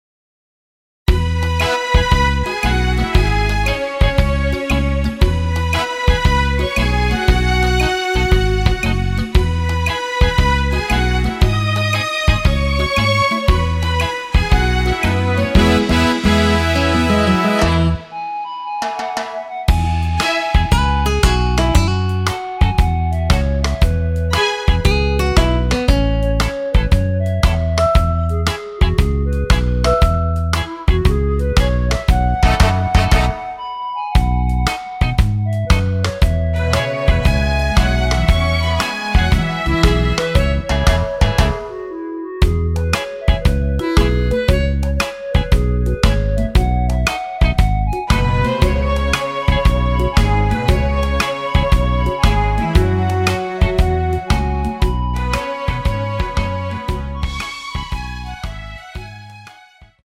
원키 멜로디 포함된 MR입니다.
앞부분30초, 뒷부분30초씩 편집해서 올려 드리고 있습니다.
중간에 음이 끈어지고 다시 나오는 이유는